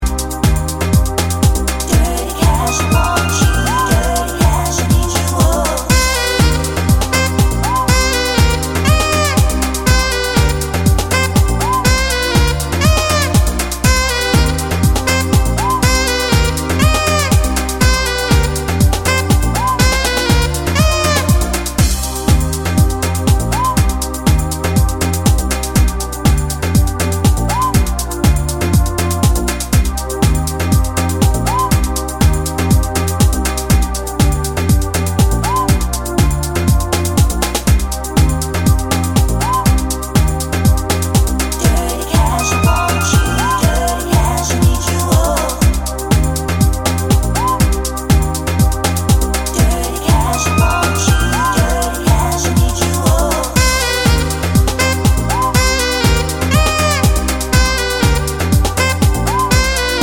With Rapper Pop (1990s) 3:51 Buy £1.50